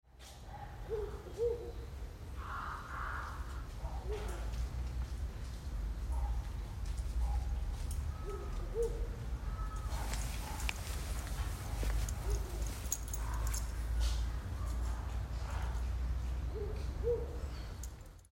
2025年5月13日の井の頭公園での野鳥観察記録
5月13日フクロウの声.mp3